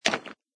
icestone2.mp3